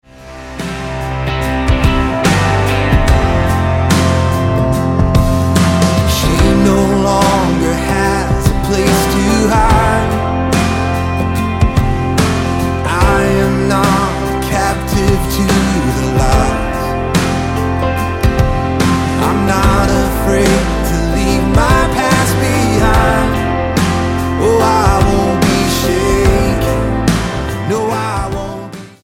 Praise & Worship